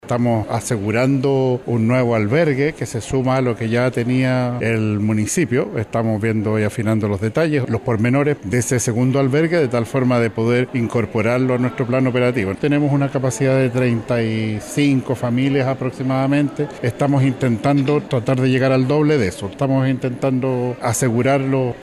En ese contexto, el delegado Presidencial de Valparaíso, Yanino Riquelme, adelantó que buscan doblar la cantidad de familias, mencionando que solo 35 serán recibidas en el Colegio España.